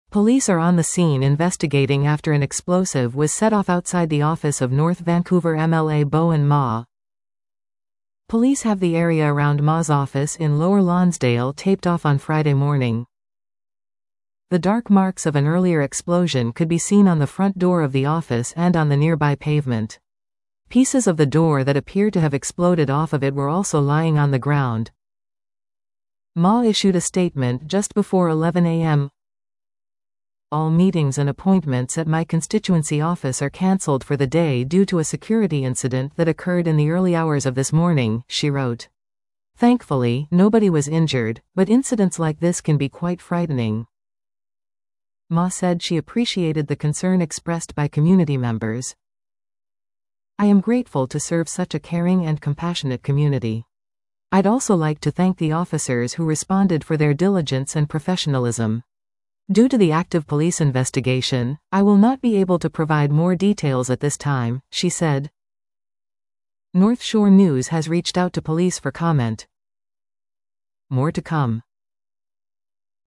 addressed members of the media at the detachment office Friday afternoon.